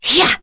Seria-Vox_Attack4.wav